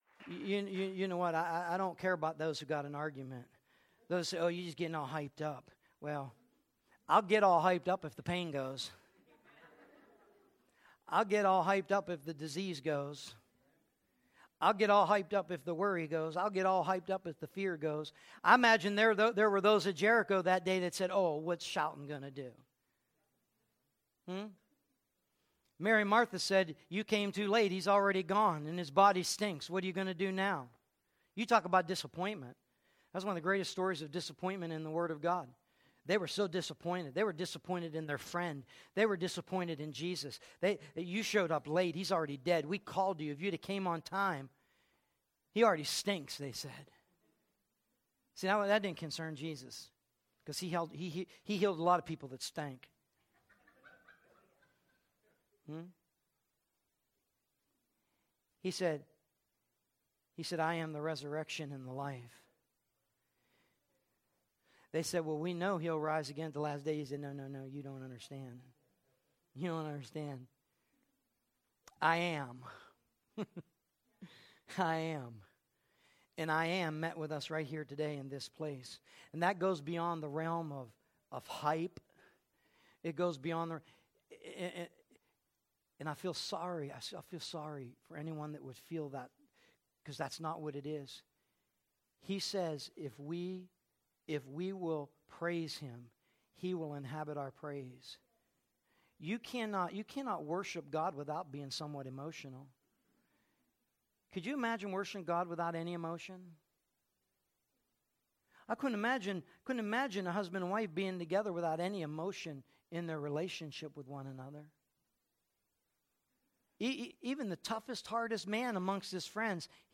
“180128_0637.mp3” from TASCAM DR-05.